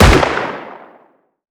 CookoffSounds / shotrocket / close_2.wav
Cookoff - Improve ammo detonation sounds